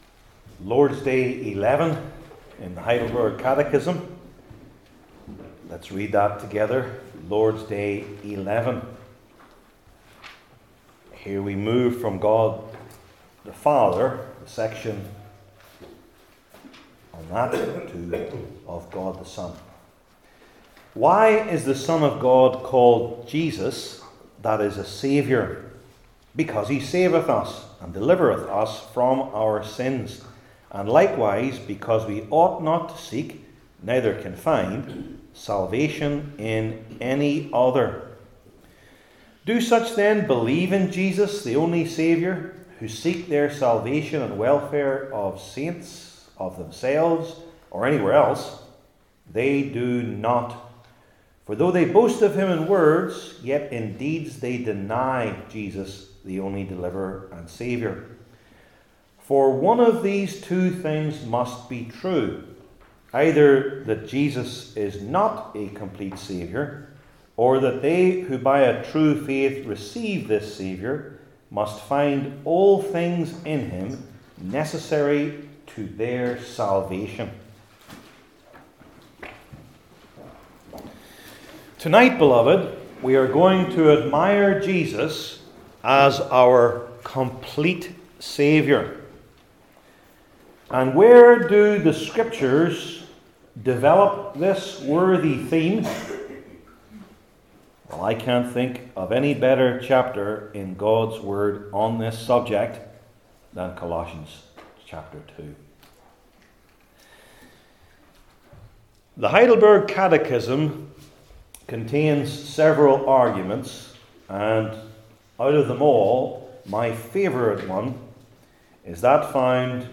Heidelberg Catechism Sermons I. His Fulness II.